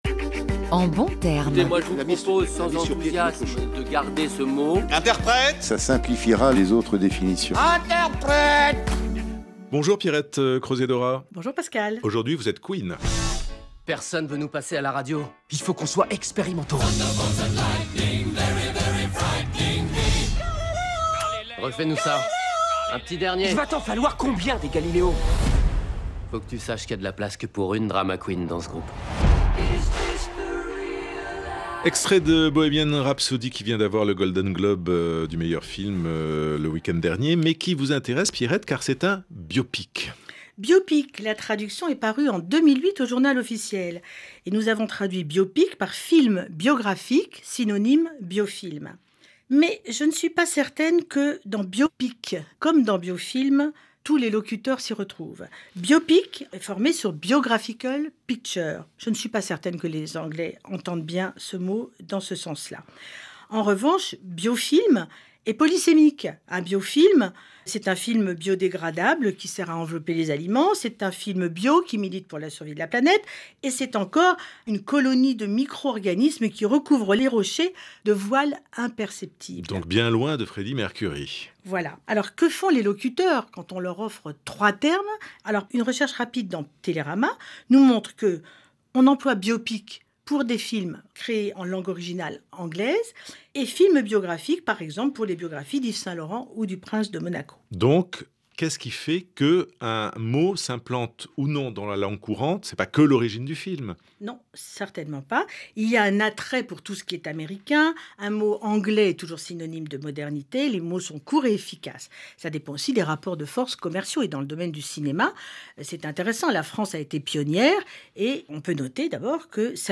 La DGLFLF lance « En bons termes », une chronique radiophonique mensuelle